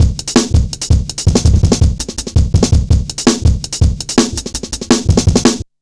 Index of /90_sSampleCDs/Zero-G - Total Drum Bass/Drumloops - 2/track 40 (165bpm)